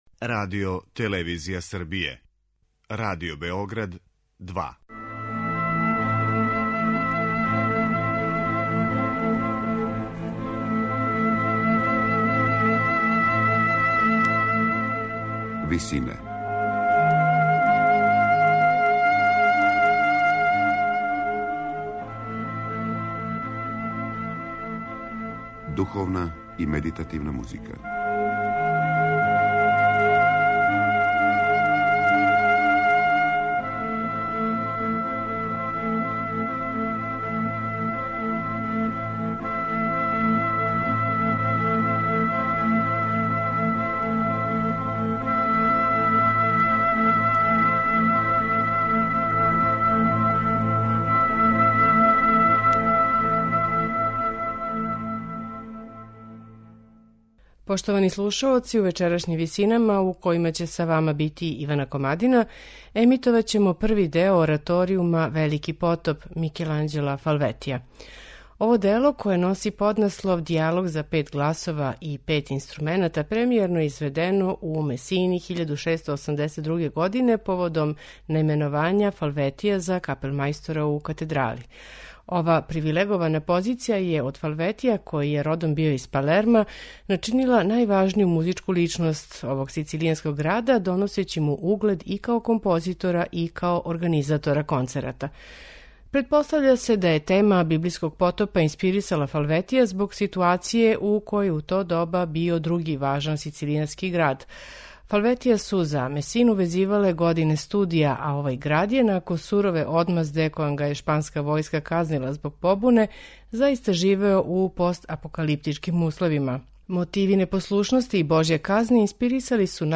Први део овог ораторијума 'Велики потоп' Микеланђела Фалветија